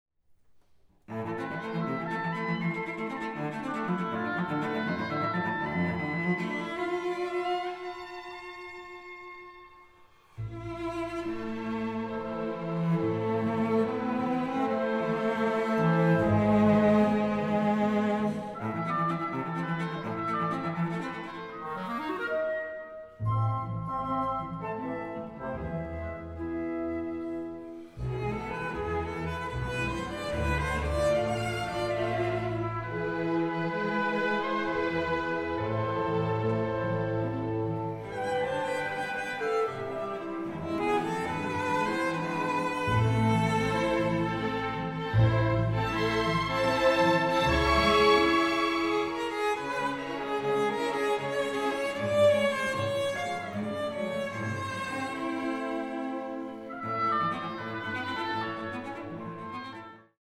Aufnahme: Rehearsal Hall, Megaron, Athens, 2024